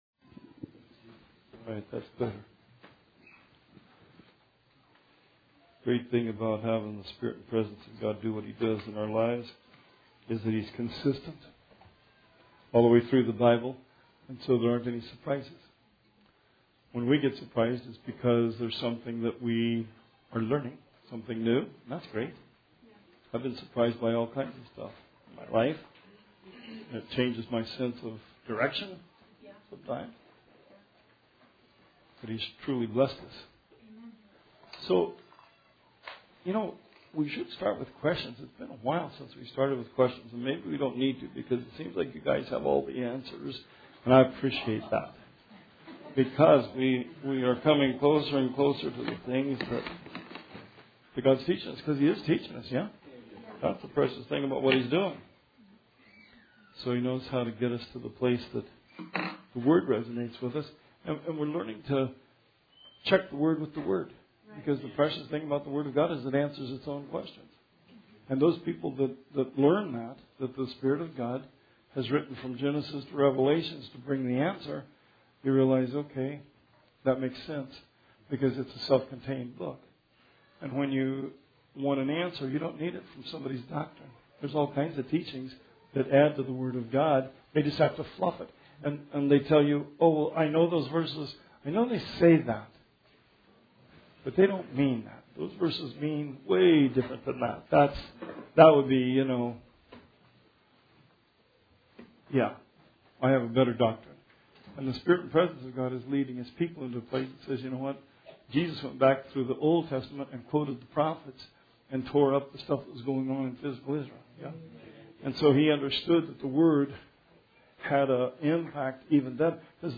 Bible Study 10/9/19